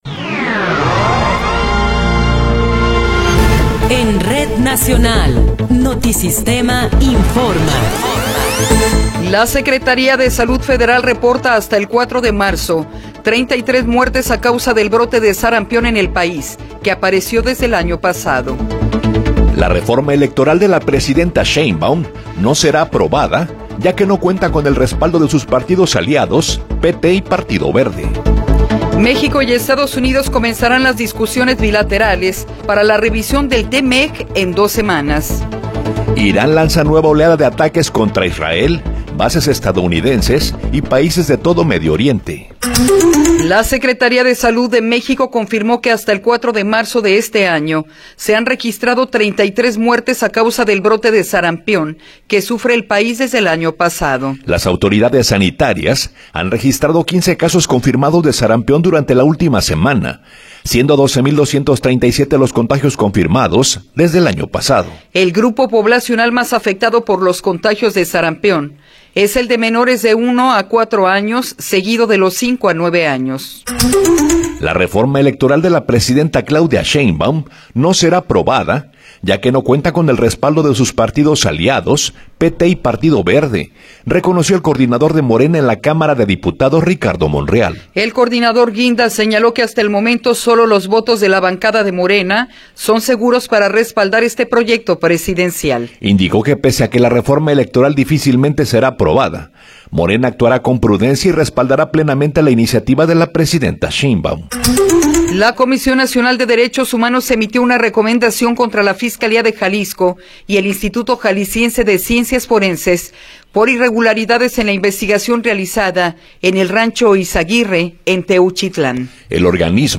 Noticiero 8 hrs. – 6 de Marzo de 2026
Resumen informativo Notisistema, la mejor y más completa información cada hora en la hora.